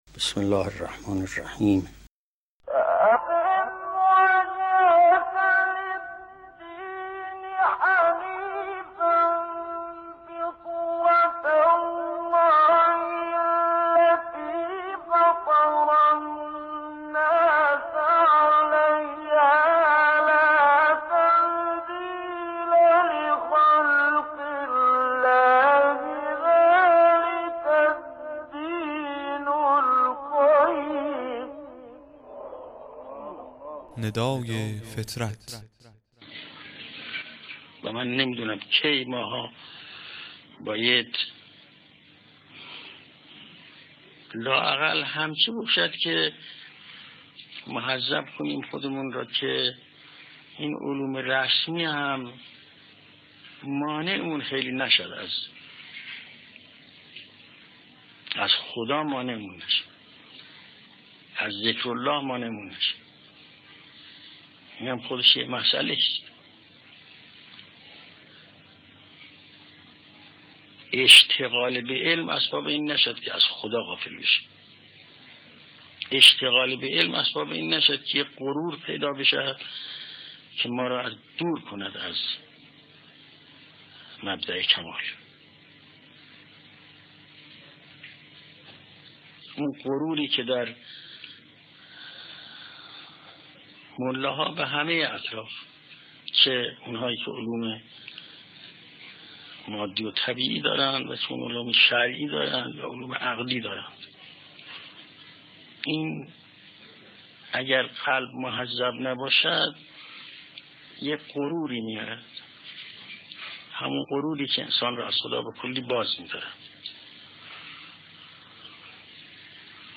قطعه صوتی کوتاه و زیبا از امام خمینی (ره)